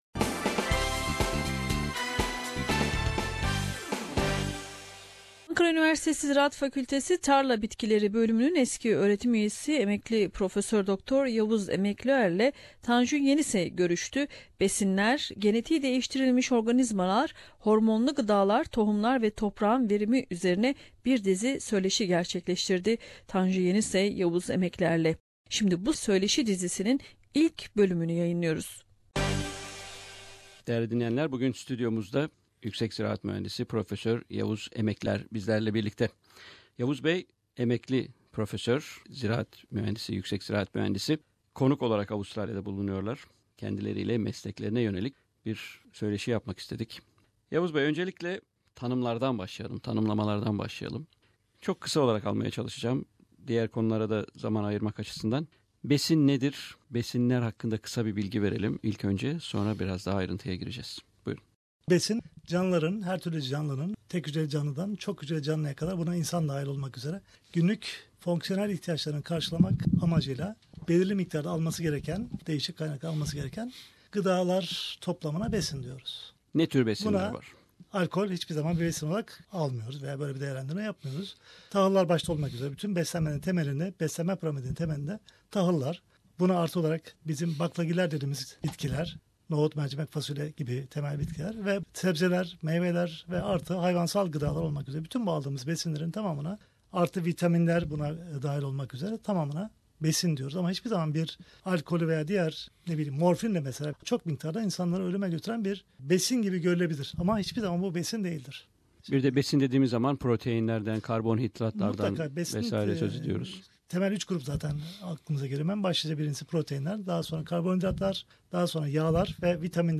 'Part one' of the interview is aired today.